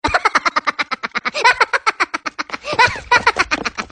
Risada Dos Minions
Risada engraçada dos Minions (Meu Malvado Favorito).
risada-minions.mp3